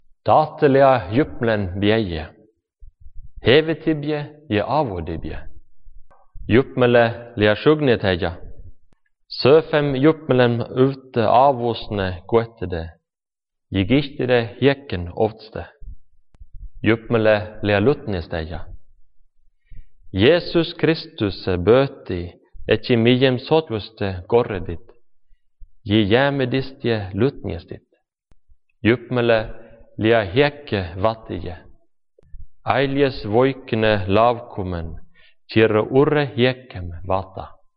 North Sámi is the largest by far, but the recording seems to contain some vowel sounds not present in North Sámi that to my ears sound like [ø]. It has a certain scandinavian flavour to it and the pronunciation of “Jesus Kristus” leads me to believe that this is a person from Norway or Sweden.